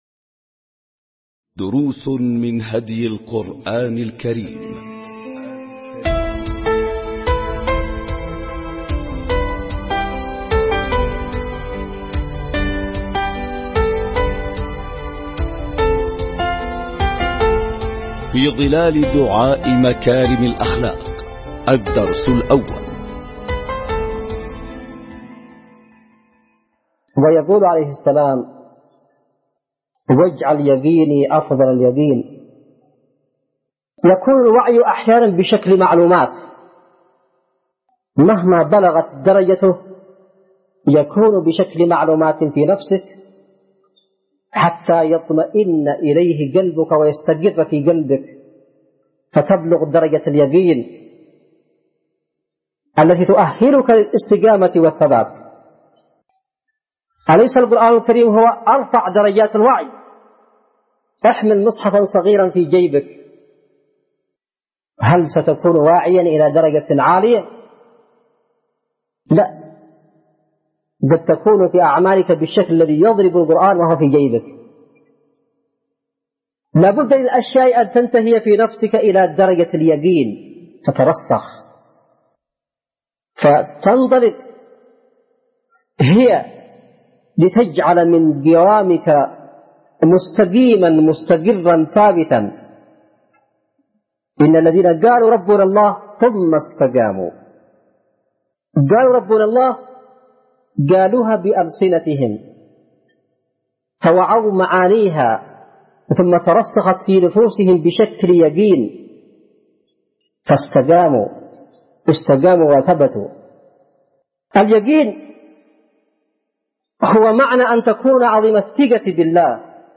🟢دروس من هدي القرآن الكريم 🔹في ظلال دعاء مكارم الأخلاق – الدرس الأول🔹 ملزمة الأسبوع | اليوم الرابع ألقاها السيد / حسين بدرالدين الحوثي بتاريخ 4/2/2002م | اليمن – صعدة